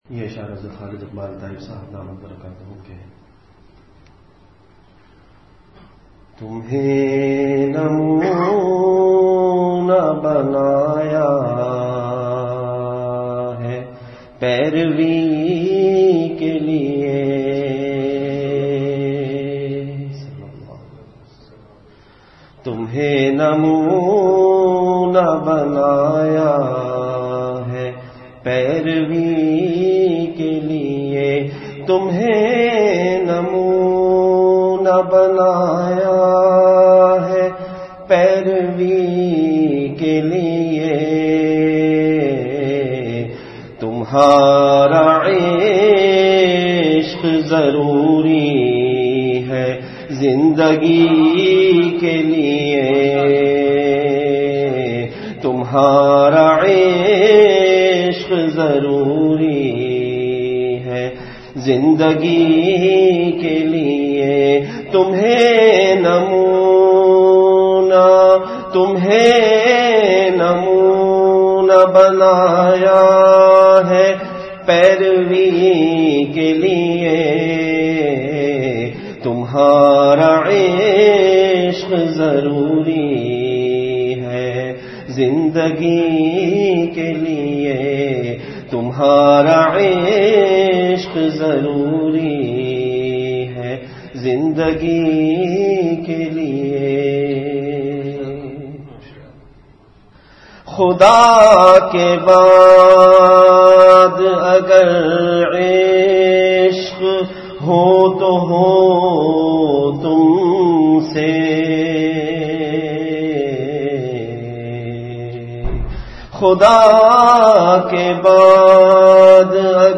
Majlis-e-Zikr · Jamia Masjid Bait-ul-Mukkaram, Karachi